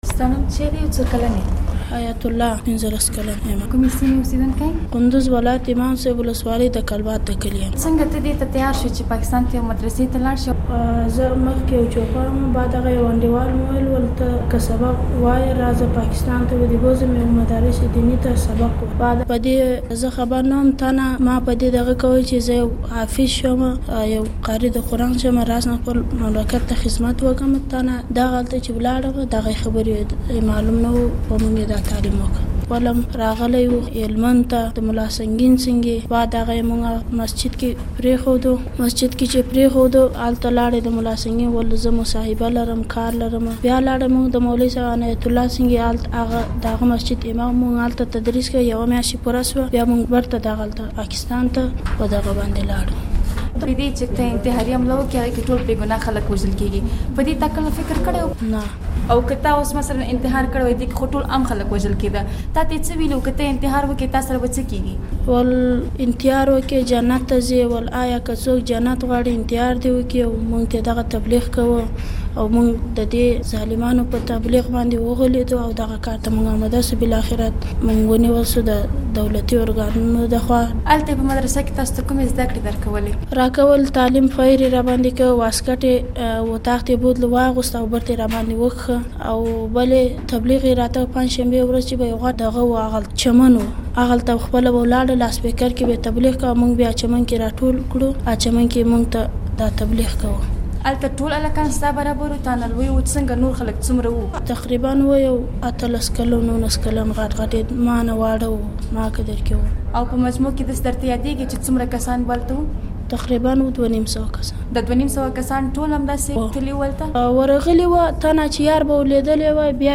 له یو ځان وژوونکي ځوان سره مرکه